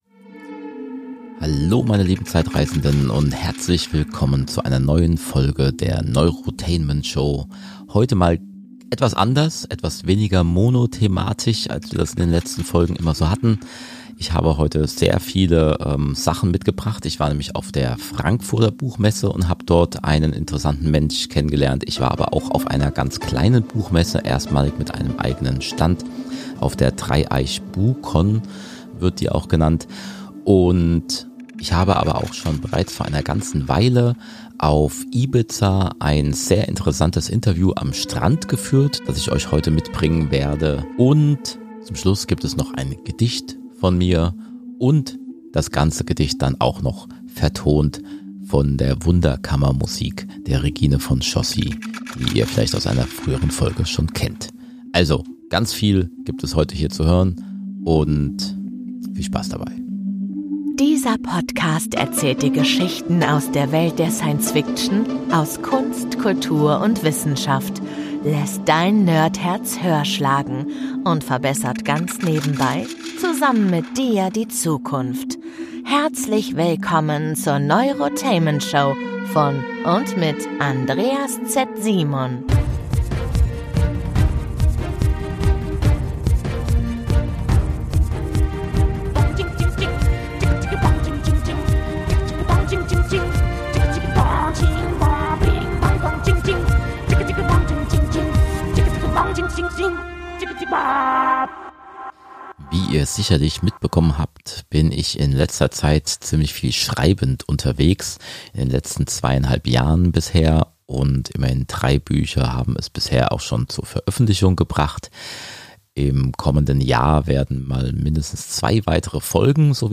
Ich war auf der Frankfurter Buchmesse und dem Buchmesseconvent Dreieich und habe da Interviews mit interessanten Autor:innen mitgebracht.